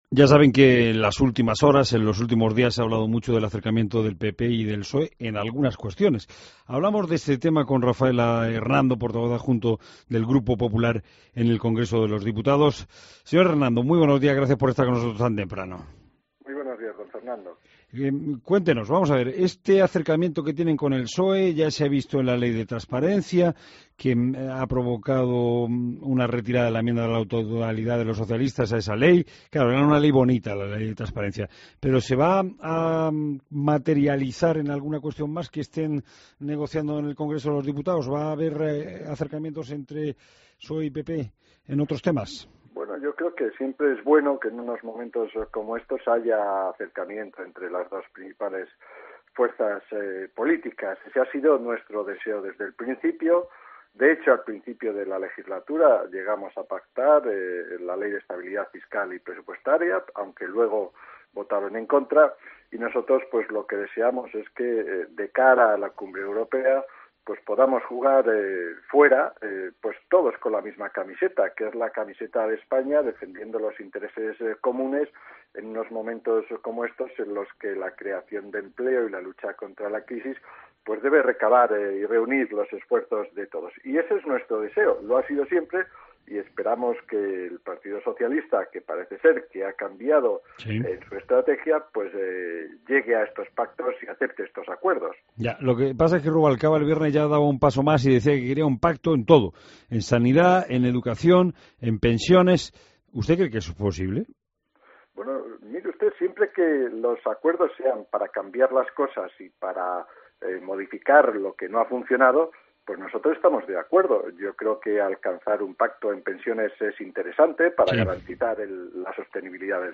Entrevista a Rafael Hernando en La Mañana Fin de Semana